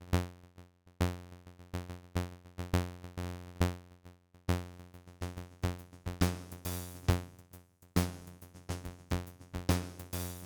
b. Envelope Follower
We gaan nu de amplitude van een drumloop gebruiken om de amplitude van de zaagtand te moduleren.